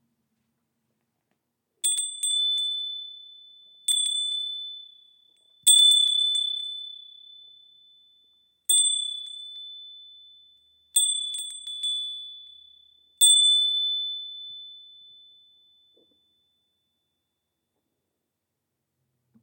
Japanese Conical Wind Chime made from cast iron
Modern Japanese Conical Wind Chime – NEW!
Each breeze brings a soft, resonant chime, creating an atmosphere of calm ideal for meditation spaces, garden sanctuaries, or any room in need of a soothing element.
Conical-Windchime.mp3